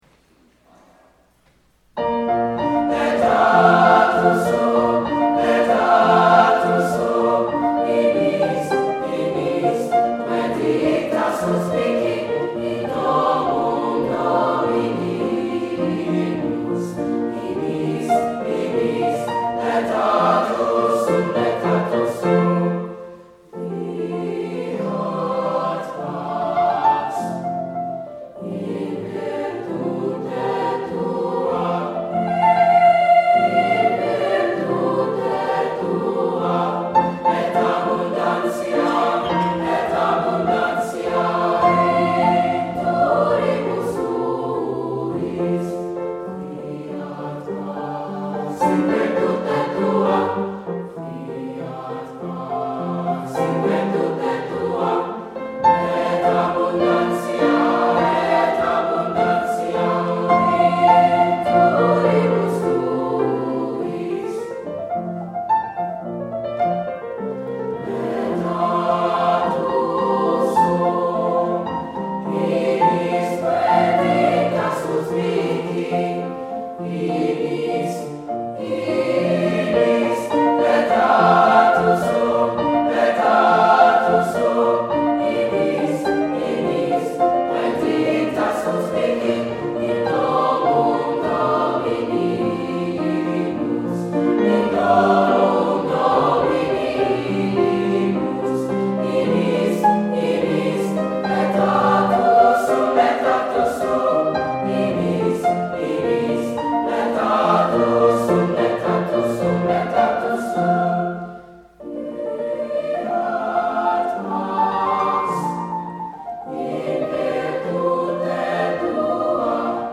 Laetatus Sum by M. Haydn Recorded at NY-ACDA State Conference